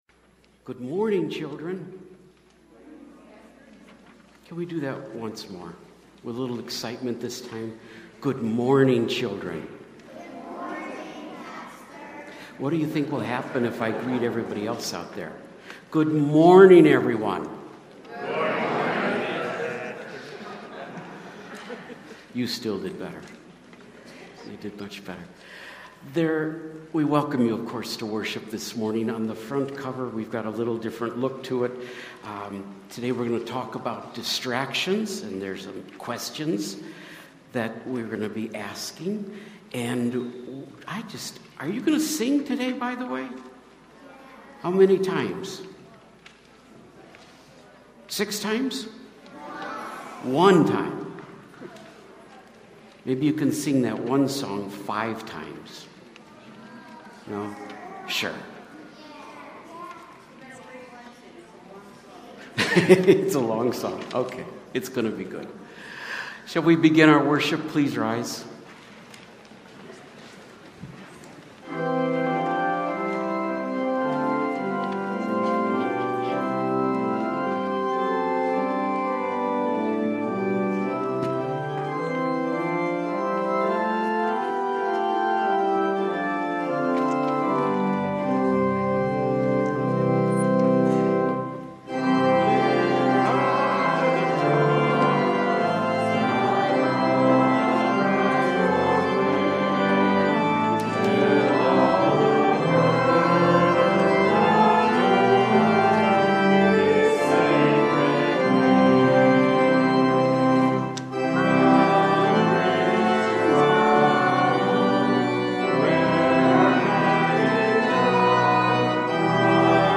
Jan 21 / Worship & Praise – Distractions – Lutheran Worship audio